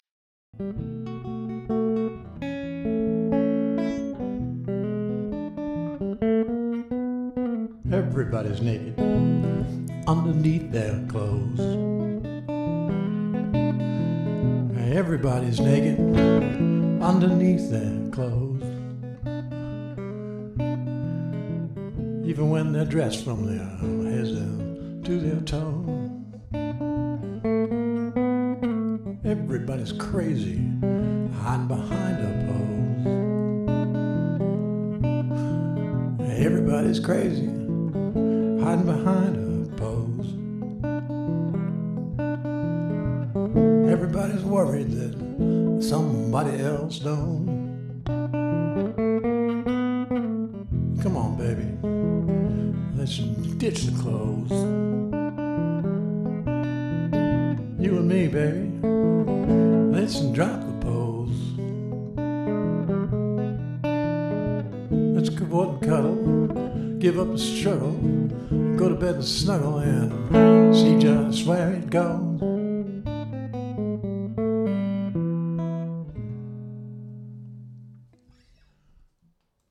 a list of songs for Open Mic